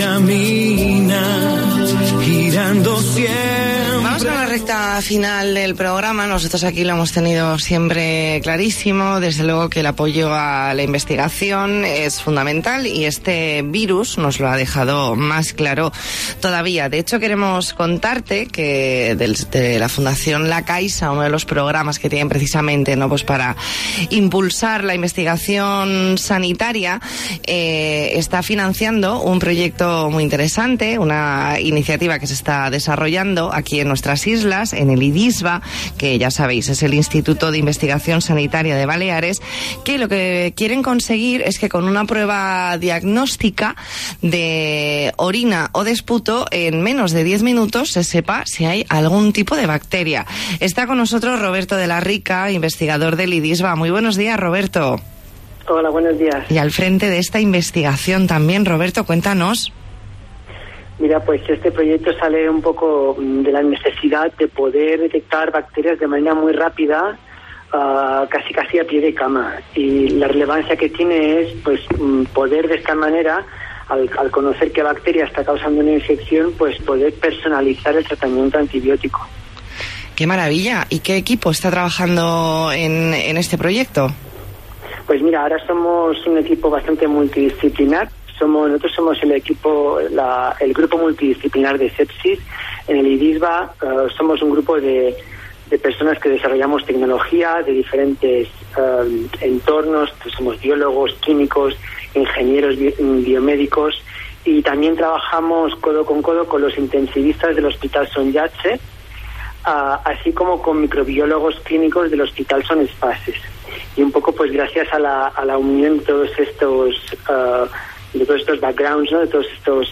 Entrevista en La Mañana en COPE Más Mallorca, lunes 27 de julio de 2020.